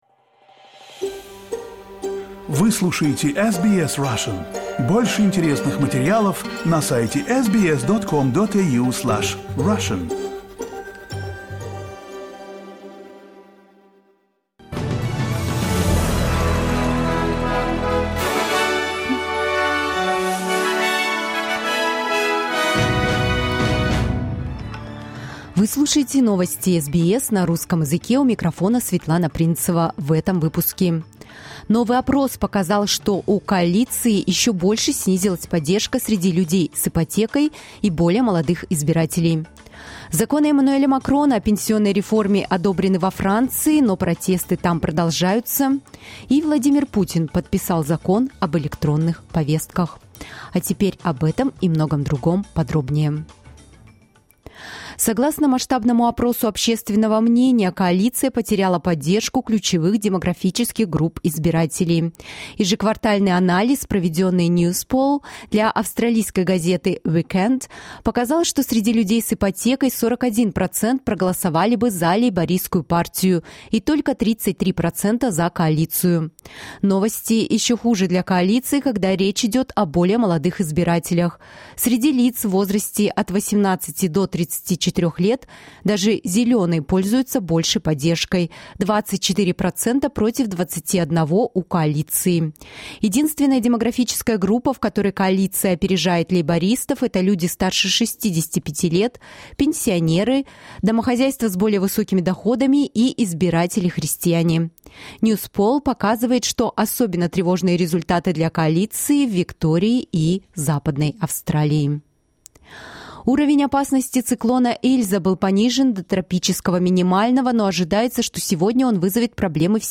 SBS news in Russian — 15.04.2023